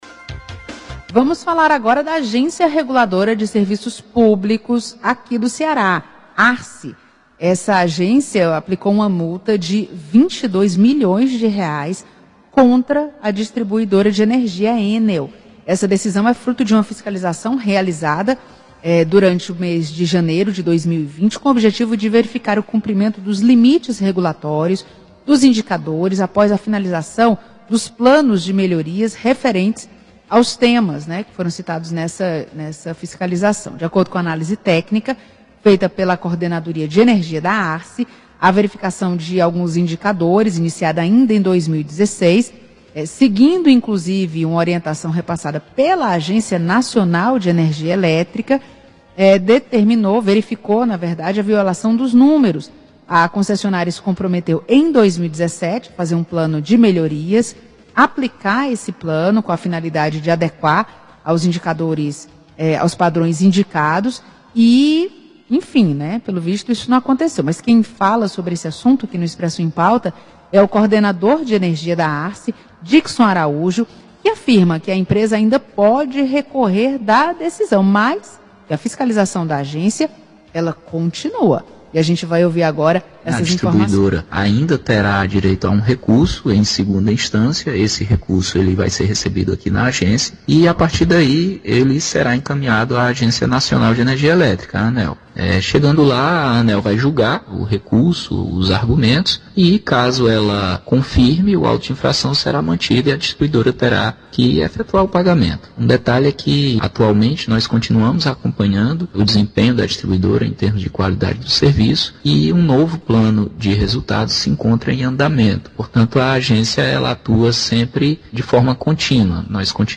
Fonte: Rádio Expresso FM – Programa: Expresso em Pauta